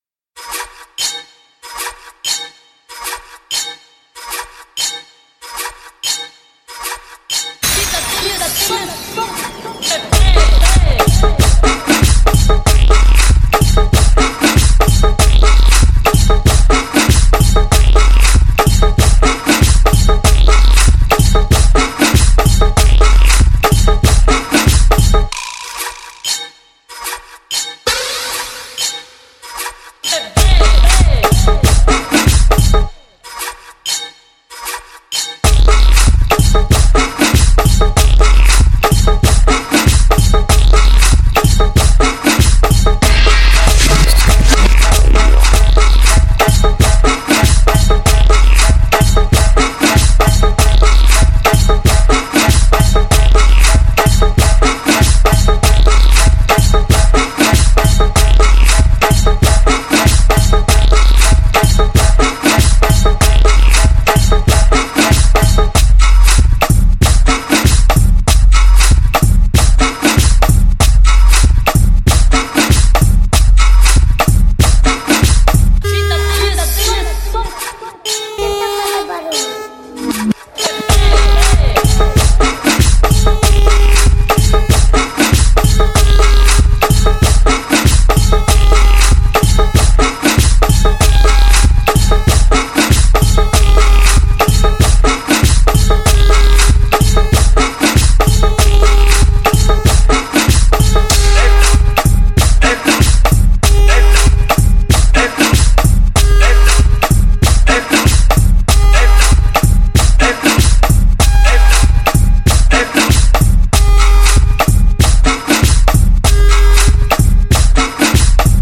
Música sincera, inadulterada, instintiva